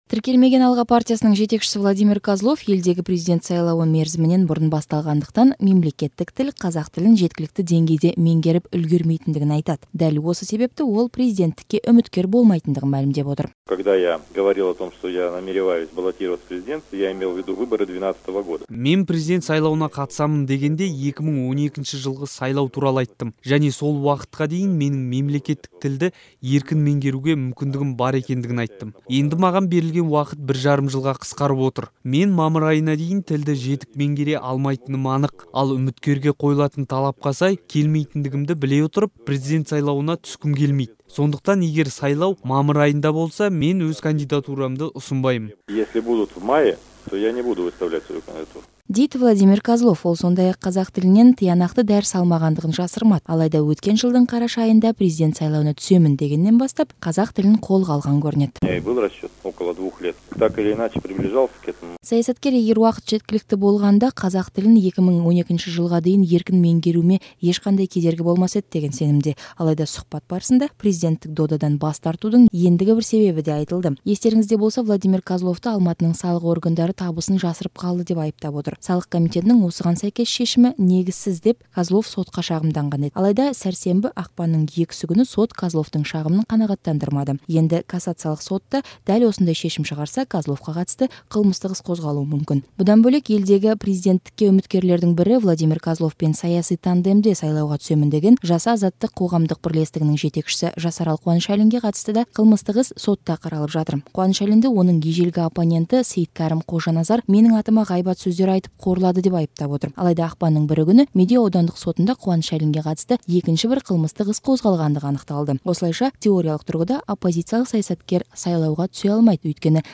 Владимир Козловтың сұқбатын тыңдаңыз